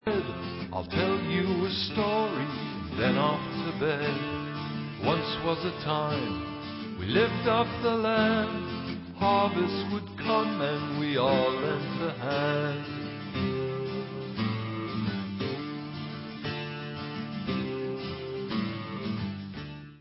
1996 studio album w.
Pop/Symphonic